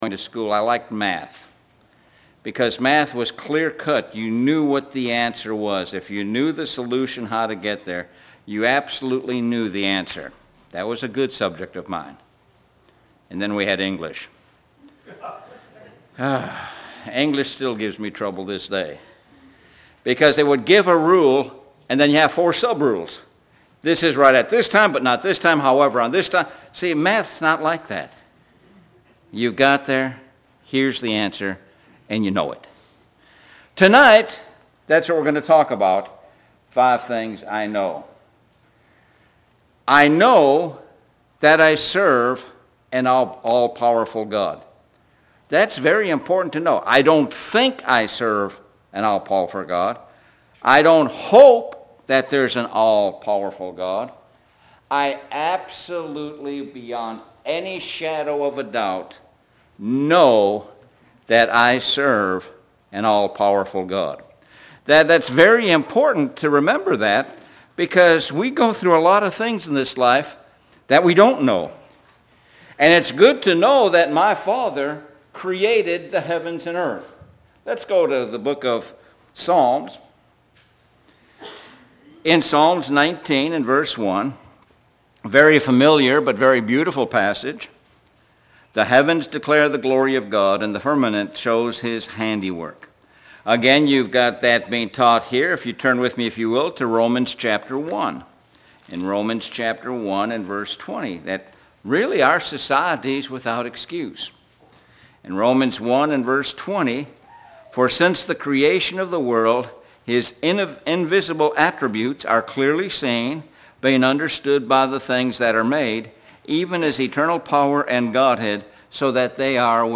Sun PM Sermon